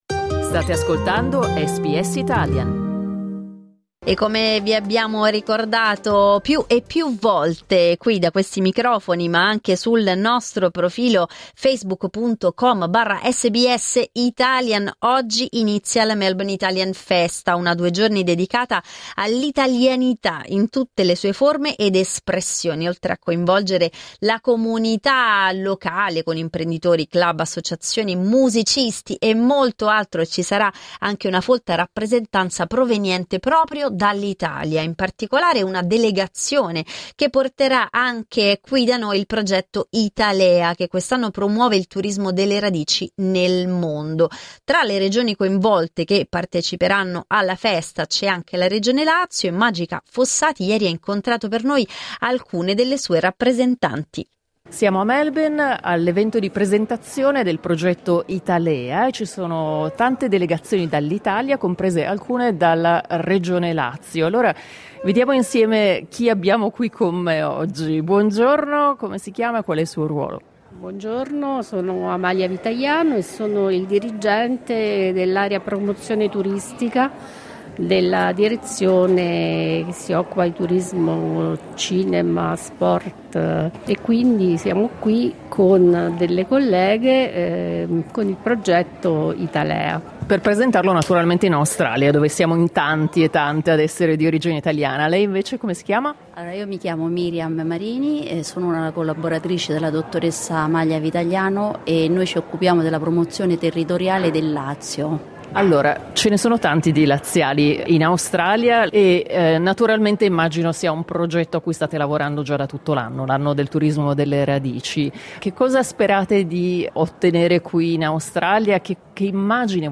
Clicca sul tasto "play" in alto per ascoltare le interviste LISTEN TO Lo Zecchino d'Oro a Melbourne, un ponte tra diverse generazioni SBS Italian 05:39 Italian Ascolta SBS Italian tutti i giorni, dalle 8am alle 10am.